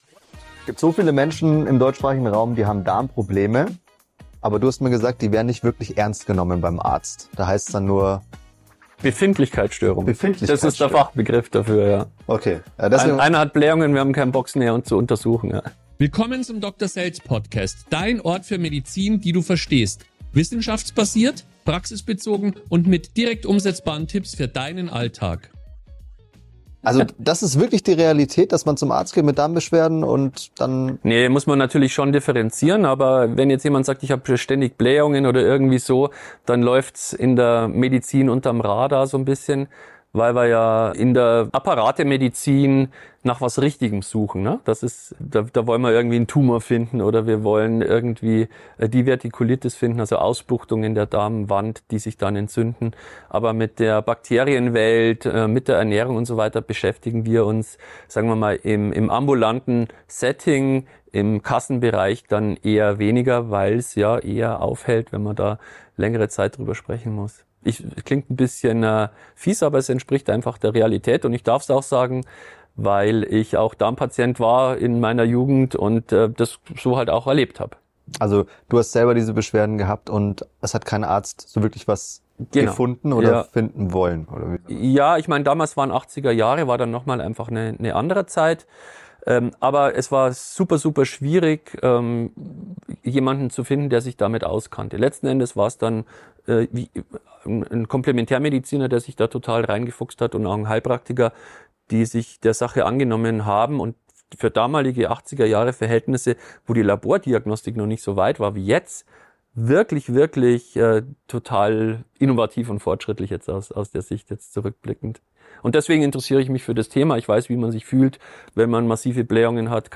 In diesem Interview